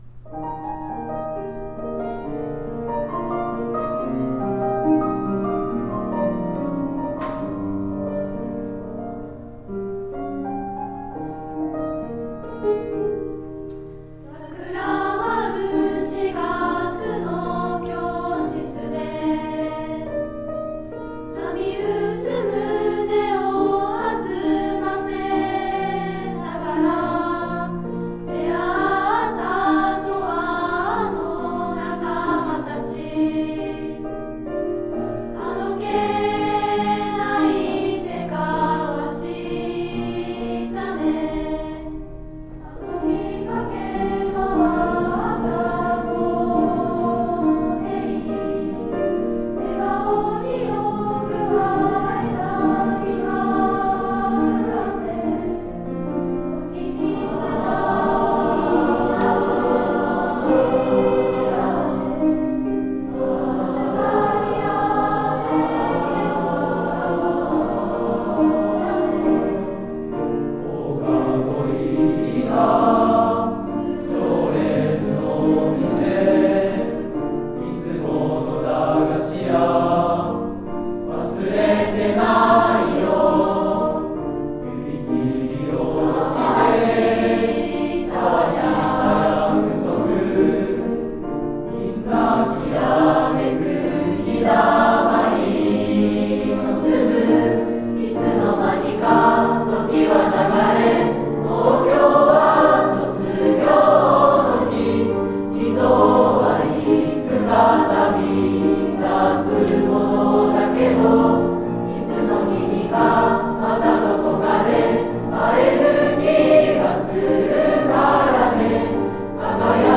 卒業式の様子
第７３回卒業証書授与式の様子です。 卒業生の歌（旅立ちの日に…）の音源をのせています。